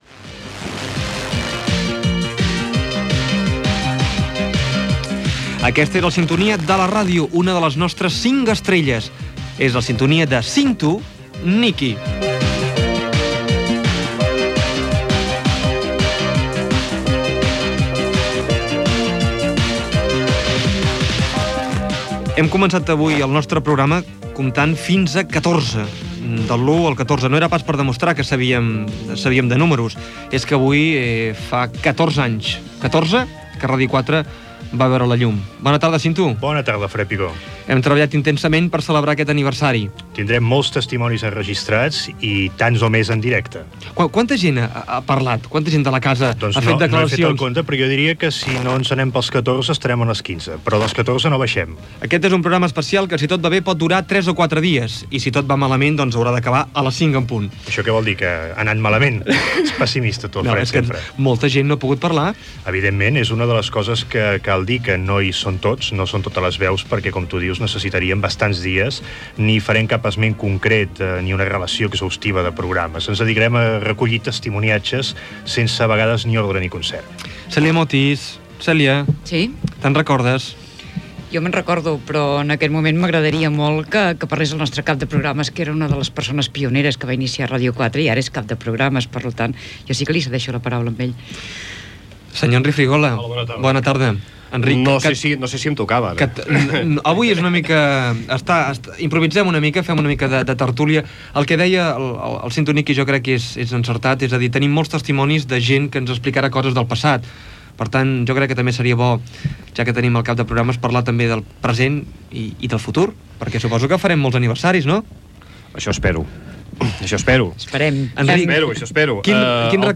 216c859117c4f69e23b38729cabca1c107dc5576.mp3 Títol Ràdio 4 Emissora Ràdio 4 Cadena RNE Titularitat Pública estatal Nom programa Tarda cinc estrelles Descripció Secció "La ràdio", dedicada als 14 anys de Ràdio 4. Conversa